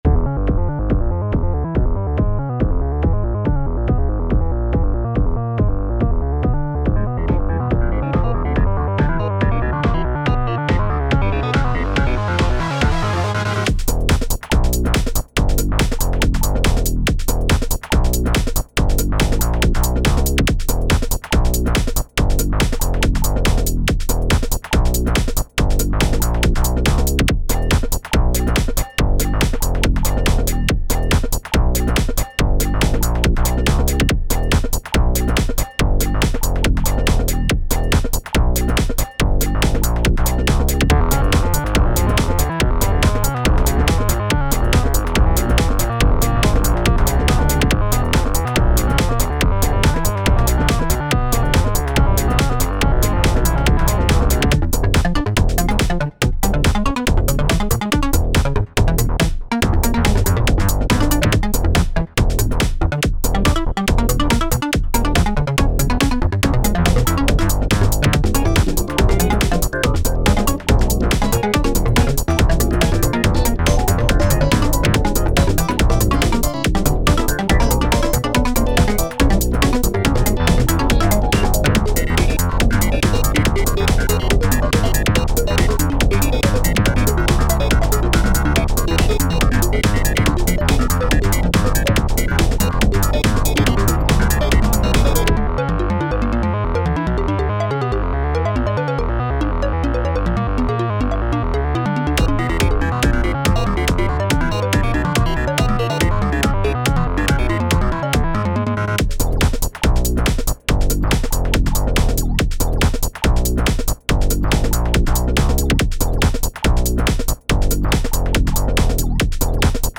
デジタルな雰囲気でノリがいい楽曲。
タグ: Beat EDM ゲーム 変わり種 電子音楽 コメント: デジタルな雰囲気でノリがいい楽曲。